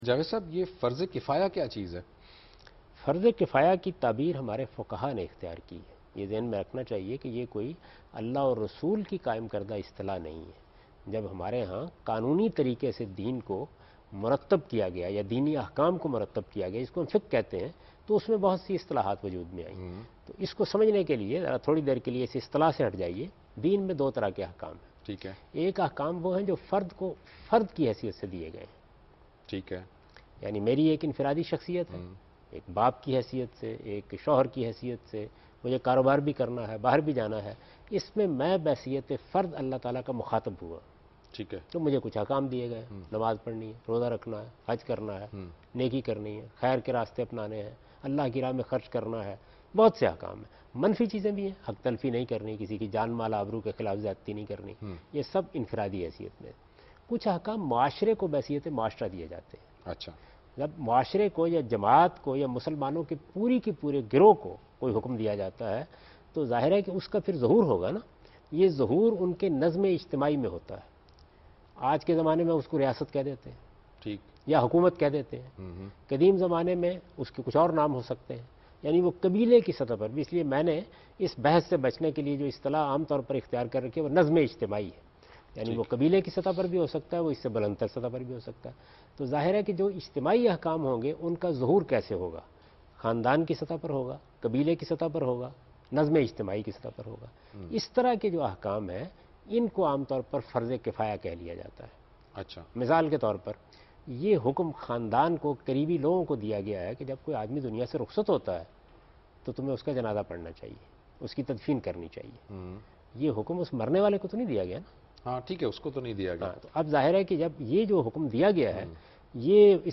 TV Programs